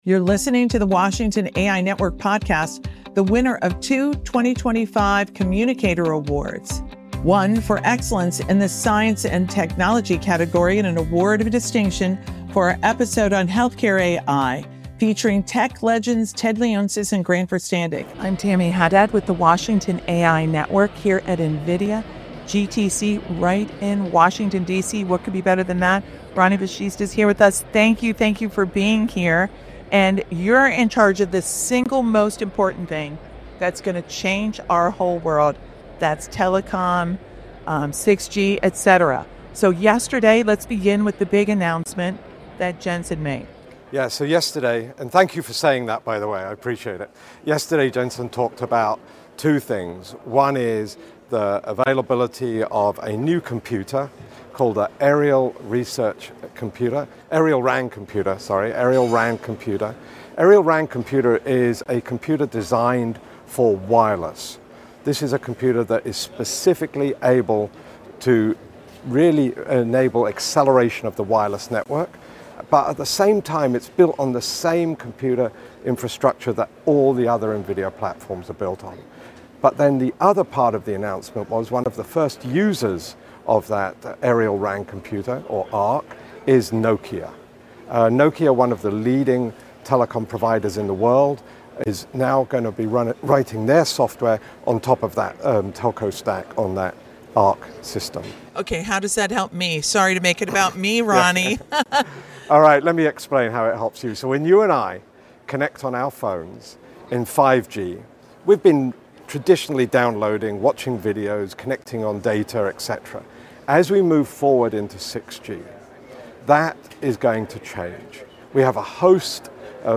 1 61: NVIDIA GTC DC: AI, Quantum, 6G, and Healthcare Innovation 24:46 Play Pause 2h ago 24:46 Play Pause Play later Play later Lists Like Liked 24:46 Recorded live at NVIDIA’s first GTC conference in Washington, D.C., this special episode of the Washington AI Network Podcast features four of NVIDIA’s top innovators and partners on how AI, supercomputing, and robotics are transforming telecom, healthcare, and quantum computing.